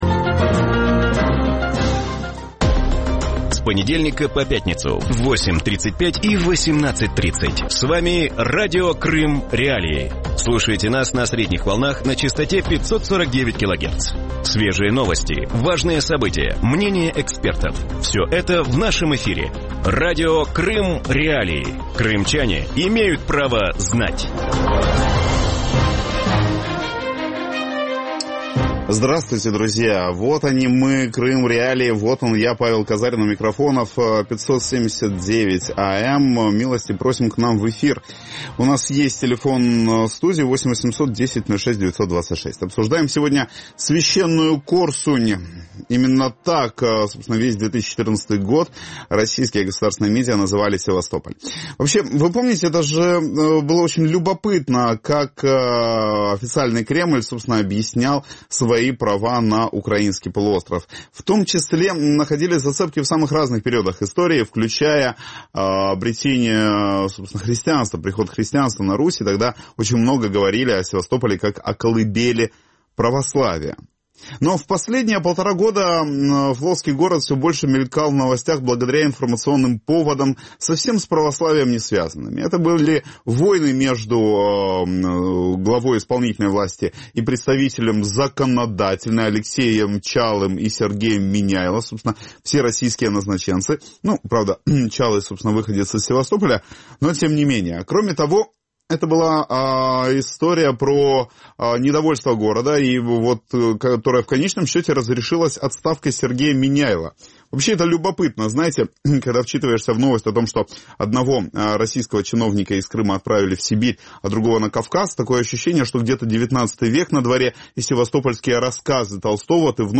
В вечернем эфире Радио Крым.Реалии обсуждают назначение Дмитрия Овсянникова на должность подконтрольного Кремлю временно исполняющего обязанности губернатора Севастополя. Что означает назначение человека «без погон» во флотский город, особенно после вице-адмирала Сергея Меняйло. Чем обернется такое назначение для города и севастопольцев.